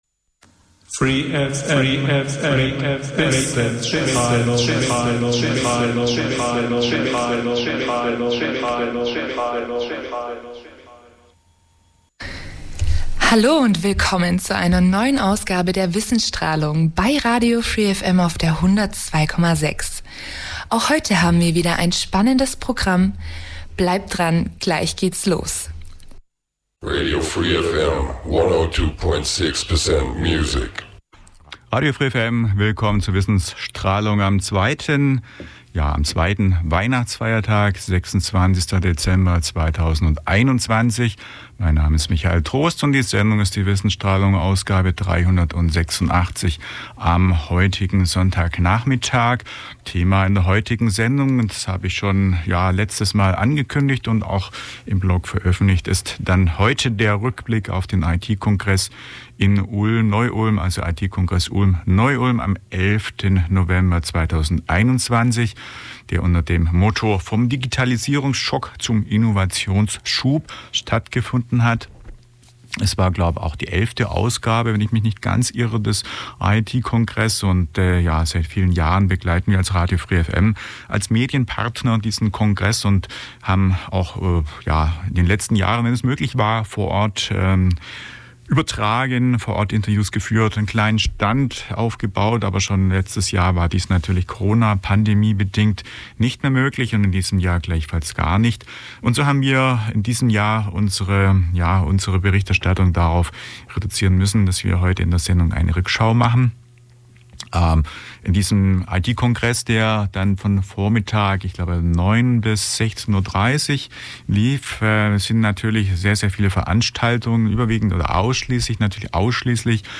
Genre Radio